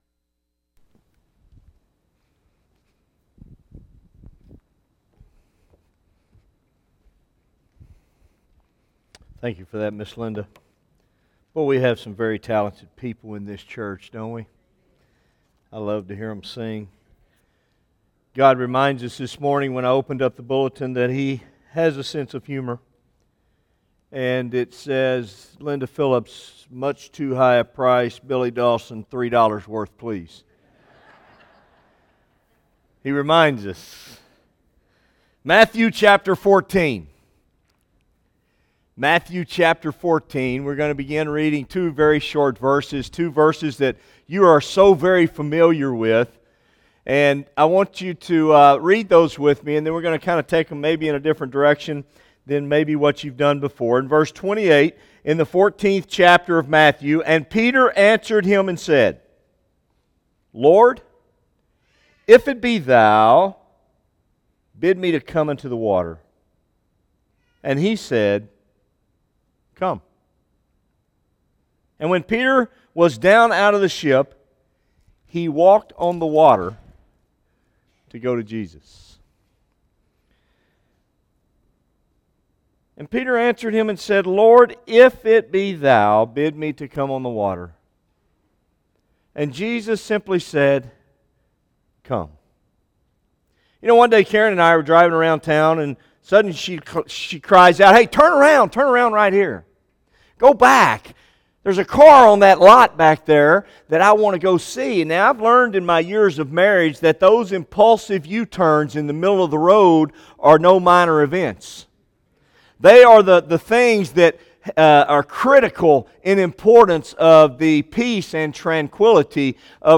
Morning worship: “$3 Dollars Worth, Please” Matthew 14:28-29
by Office Manager | Nov 21, 2016 | Bulletin, Sermons | 0 comments